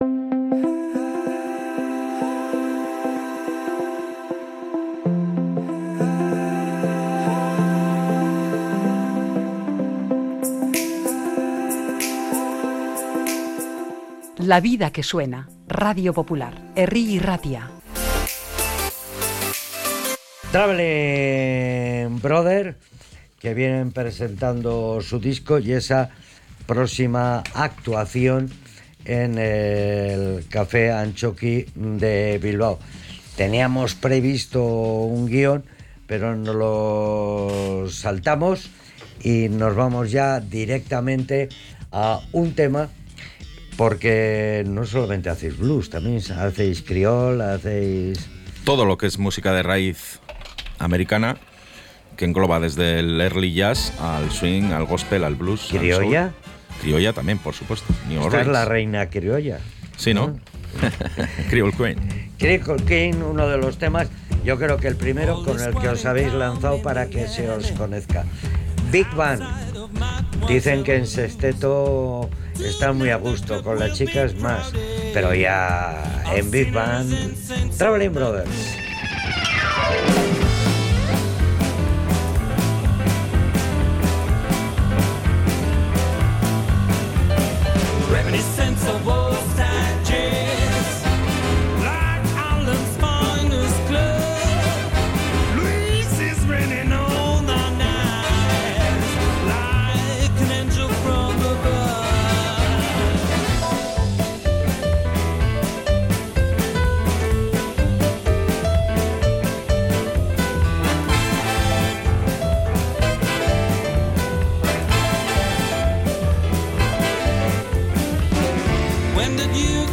La mítica banda vizcaína de raíces americanas pasó por los micrófonos de Radio Popular - Herri Irratia para presentar su undécimo disco